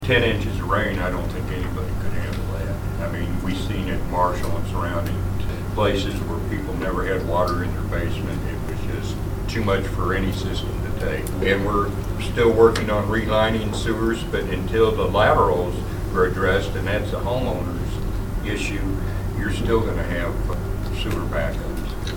Mayor Allegri says Slater isn’t the only place with this issue, and many surrounding towns are facing the effect of flooded homes.